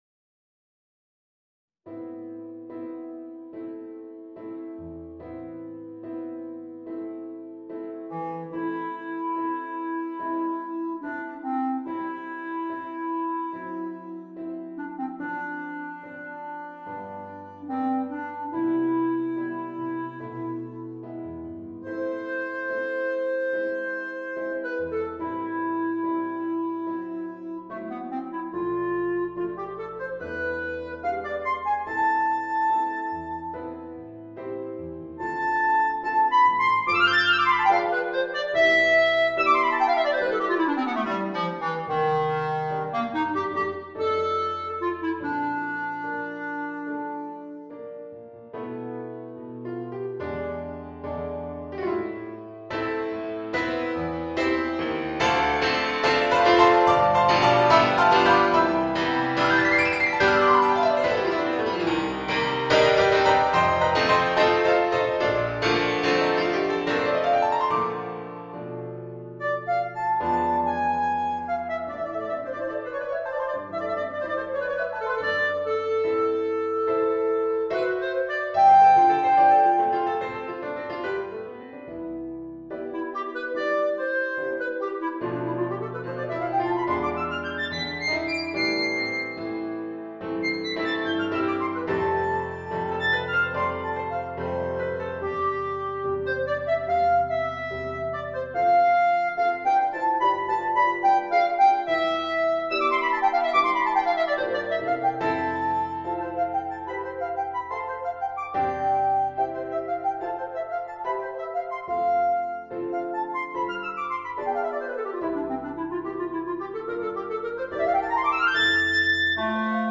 Bass Clarinet & Piano